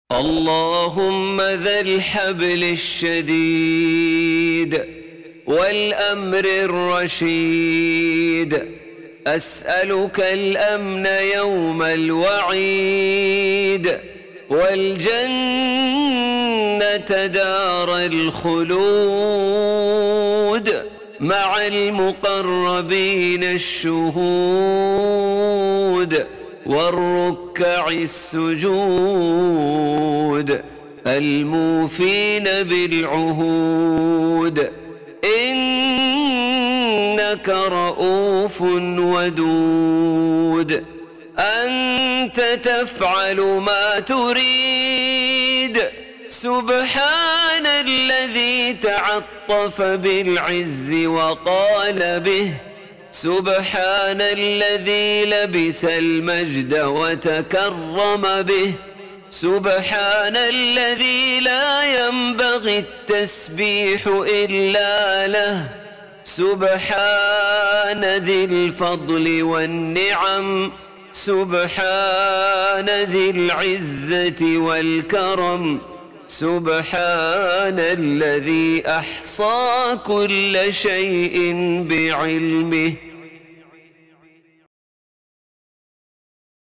لا يوجد محتوى نصي لهذا الدعاء.